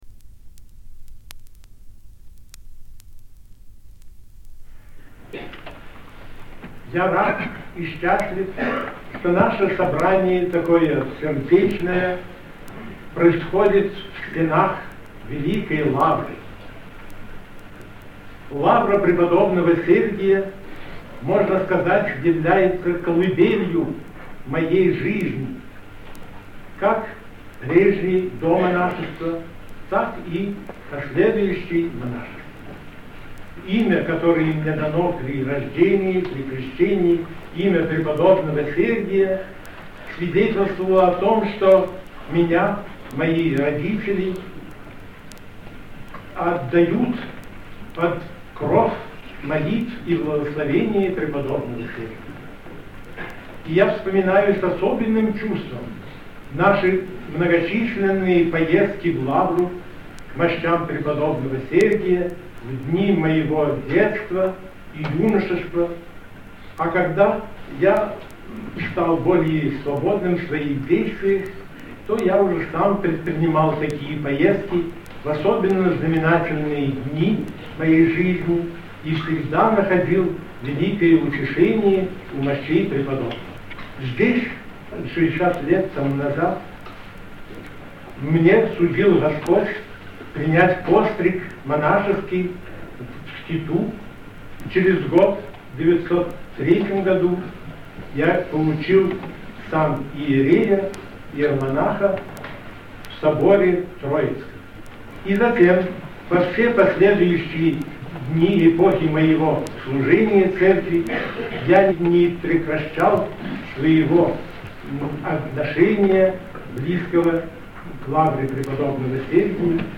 Запись с пластинки "Патриарх Алексий (Симанский) и его время"
6. Слово Патриарха Алексия
Празднование 85-летие Патриарха 1962 г.